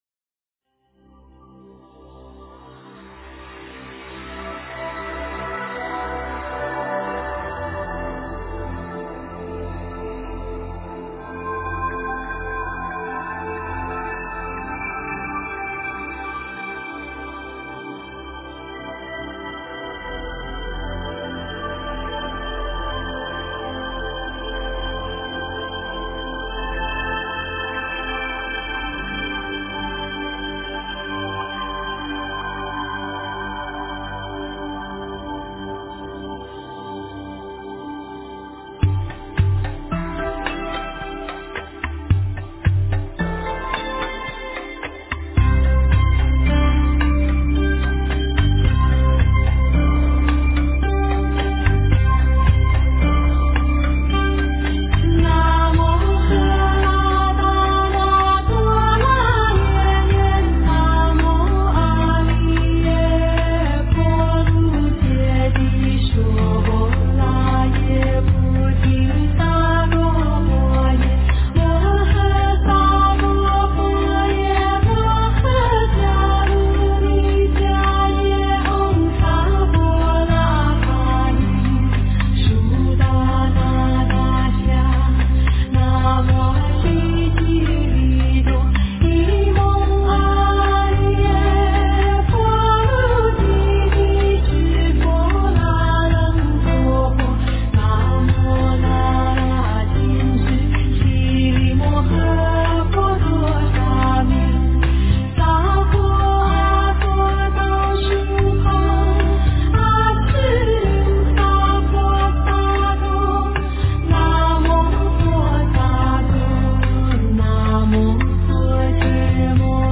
大悲咒-清音 诵经 大悲咒-清音--佛音 点我： 标签: 佛音 诵经 佛教音乐 返回列表 上一篇： 一切如来心秘密全身舍利宝箧印陀罗尼 下一篇： 十一面观自在菩萨根本真言-快速版 相关文章 观世音菩萨普门品--寺院木鱼唱诵版 观世音菩萨普门品--寺院木鱼唱诵版...